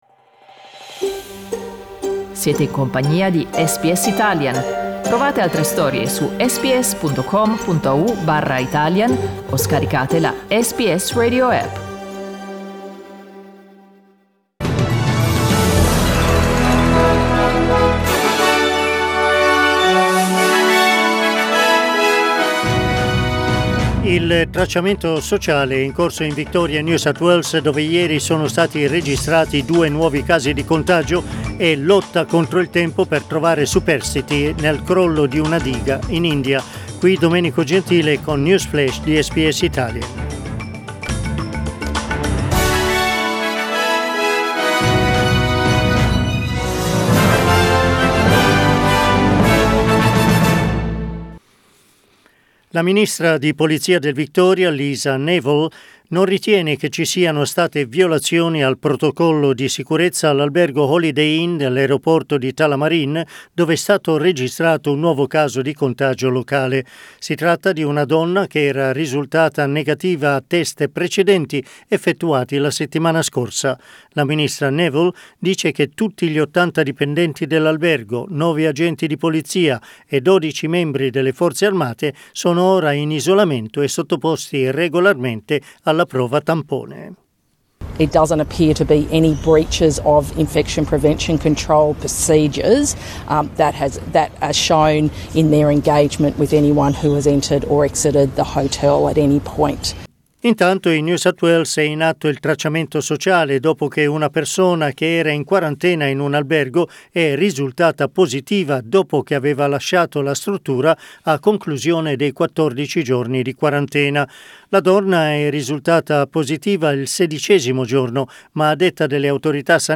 News Flash Monday 8 February 2021
Our news update in Italian.